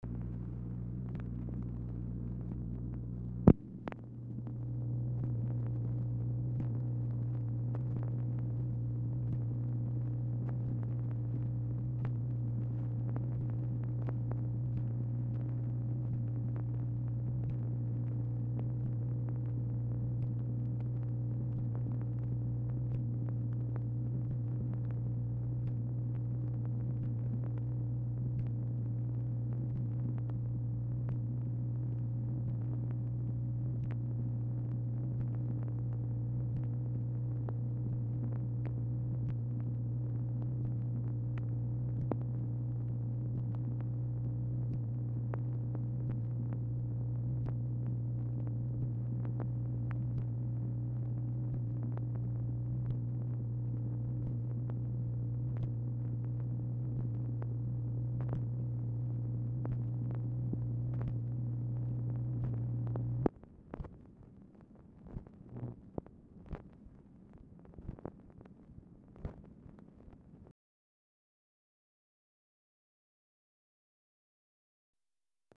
Format Dictation belt
Location Of Speaker 1 LBJ Ranch, near Stonewall, Texas
White House Telephone Recordings and Transcripts Speaker 2 MACHINE NOISE Specific Item Type Telephone conversation